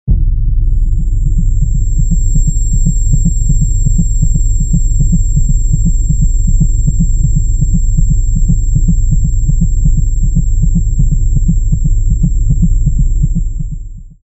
Звуки шума в ушах
Здесь можно послушать и скачать примеры гула, звона, шипения и других фантомных шумов, которые некоторые люди воспринимают субъективно.